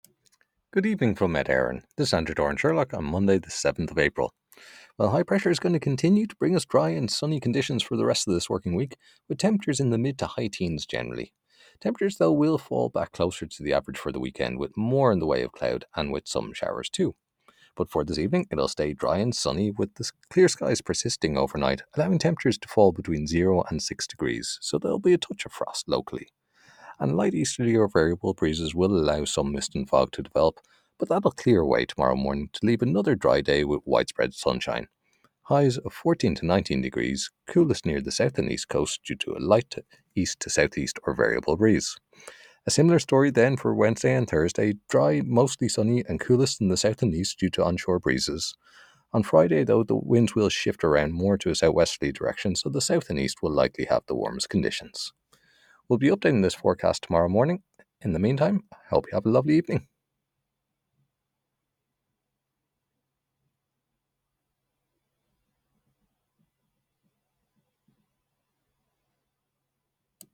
Weather Forecast from Met Éireann / Ireland's Weather 6pm Monday 7 April 2025